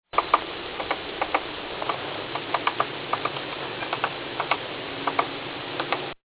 Sphyrapicus nuchalis (red-naped sapsucker)
A male Red-Naped Sapsucker pecking away at a Salt Cedar at the edge of Peck's Lake [Arizona]. He worked the tree for 20 minutes or so while I was recording Coots.